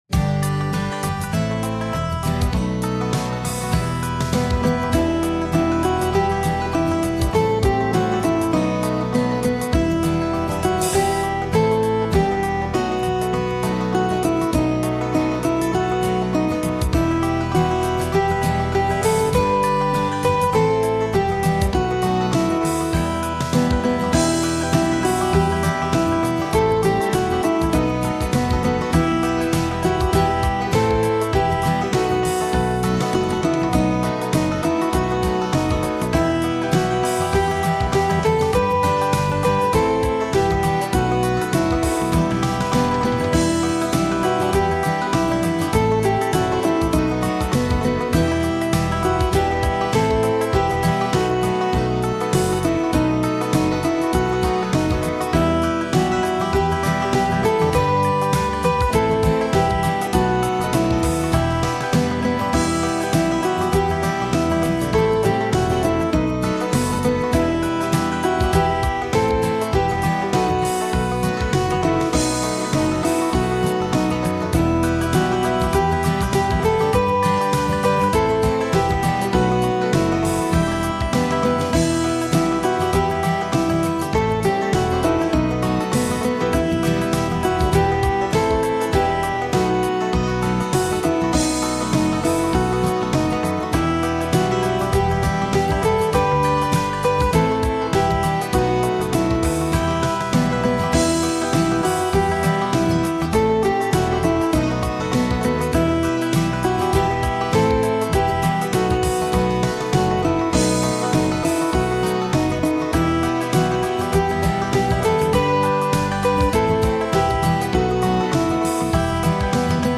My backing is at 100 bpm.